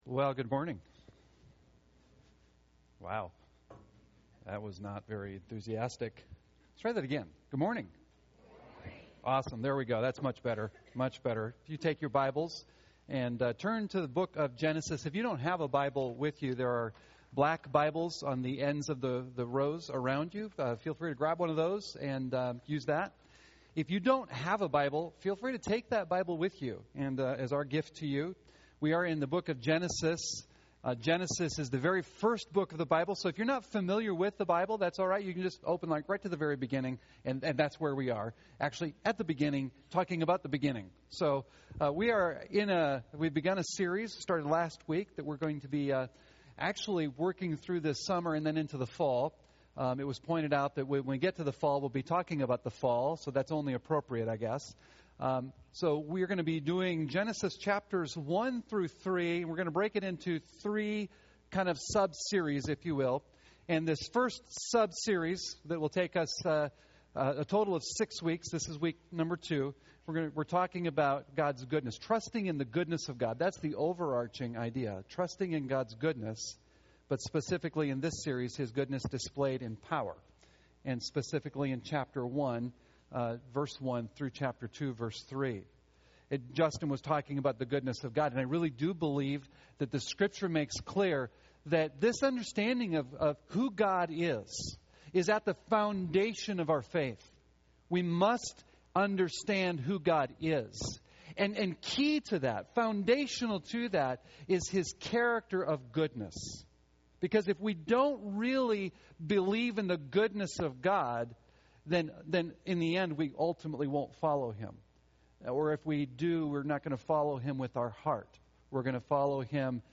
Listen to the latest Sunday sermons from Cornerstone Berean Church.